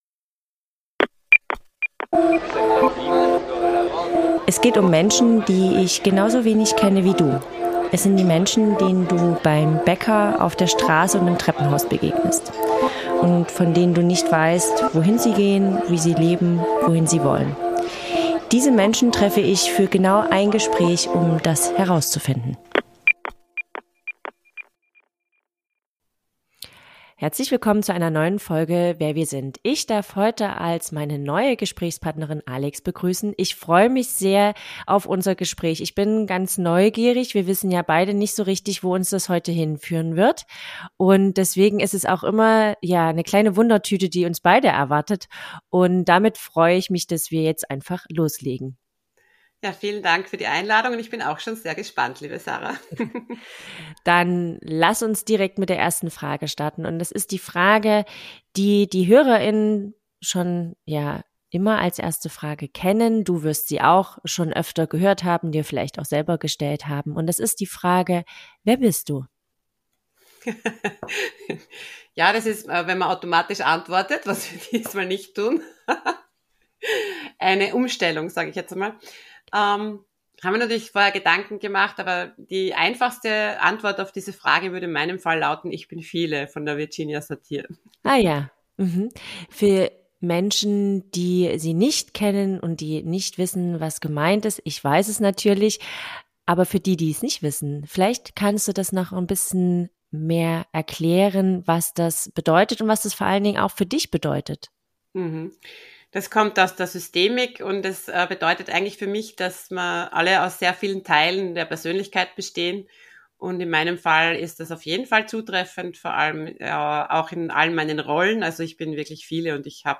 Ein Gespräch über das Überwinden der Opferrolle, über Verzeihen als Kraftakt und darüber, was es bedeutet, in einer sich verändernden Welt seinen eigenen Standpunkt zu finden.